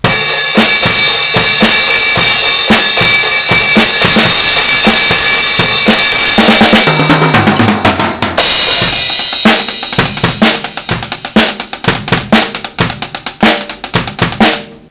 filldrum.wav